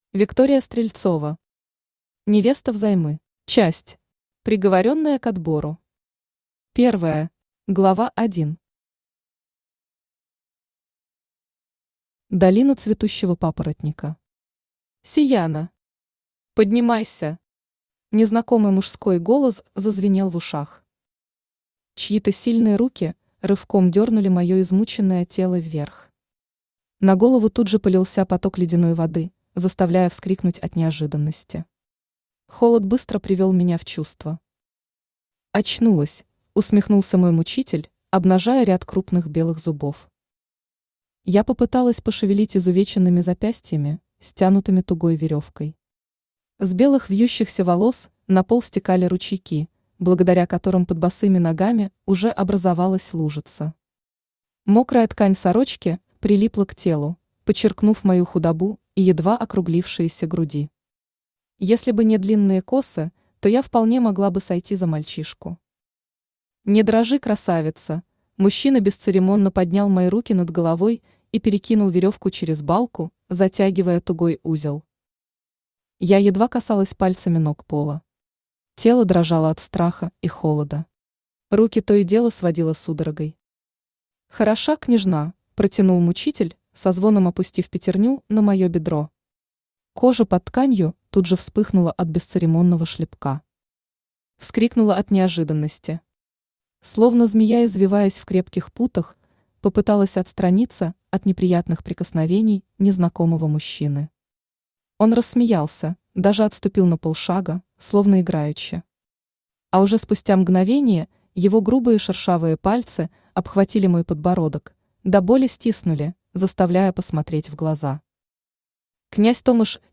Аудиокнига Невеста взаймы | Библиотека аудиокниг
Aудиокнига Невеста взаймы Автор Виктория Стрельцова Читает аудиокнигу Авточтец ЛитРес.